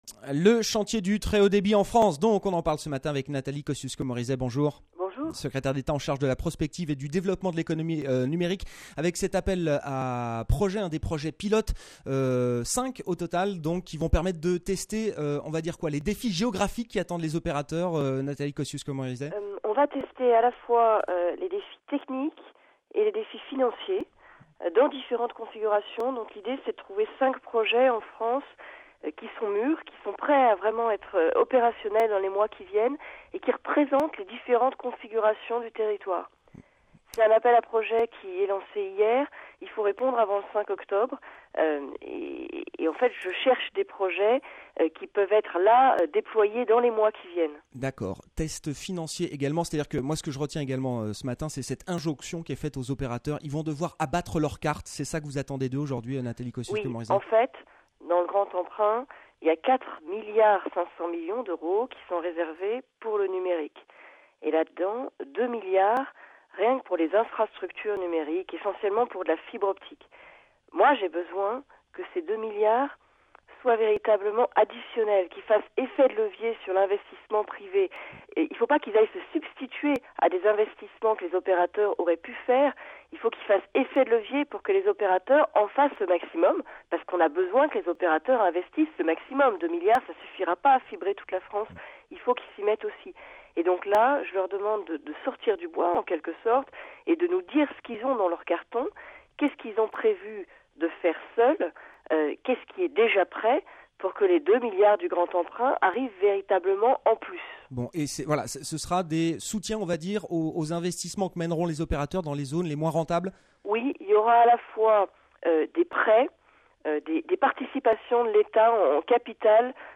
Nathalie Kosciusko-Morizet, la secrétaire d’État en charge de la prospective et du développement de l’économie numérique, était l’invitée le jeudi 5 août de Good Morning Business sur BFM Radio.